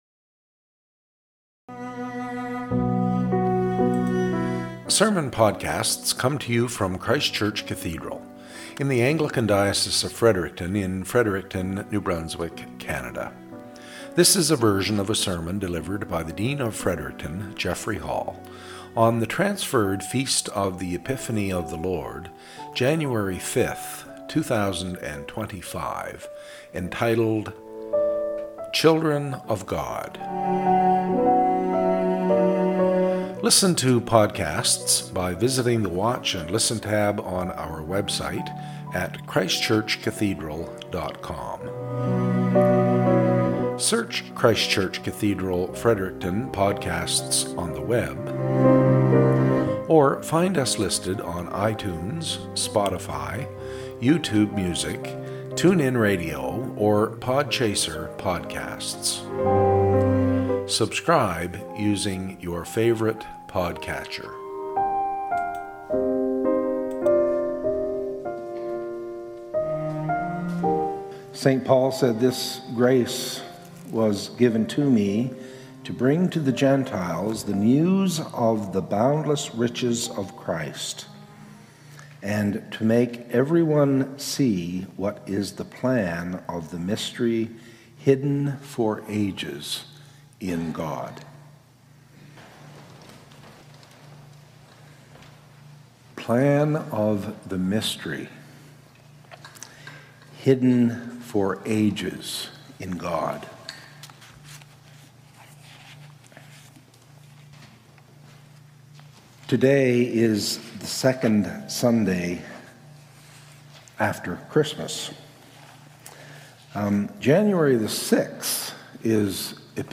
SERMON - "Children of God"